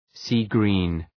Προφορά
{,si:’gri:n}